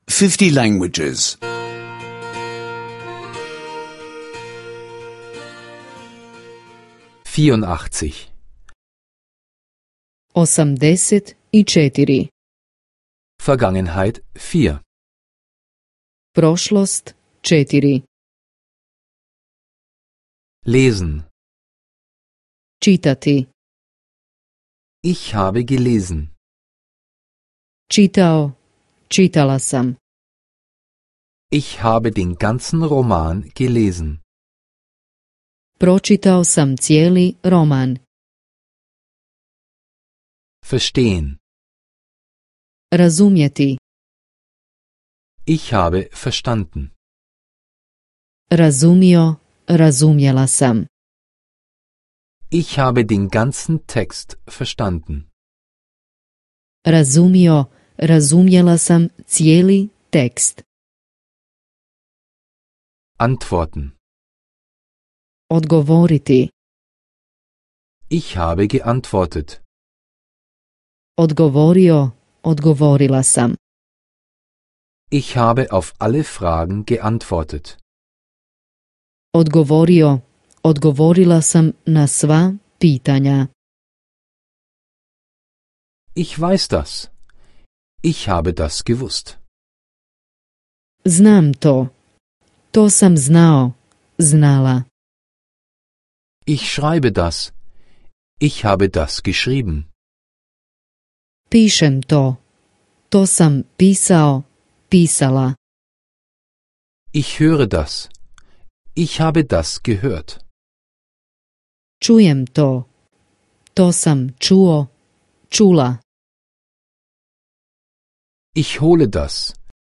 Kroatisch Audio-Lektionen, verfügbar zum kostenlosen Download per Direktlink.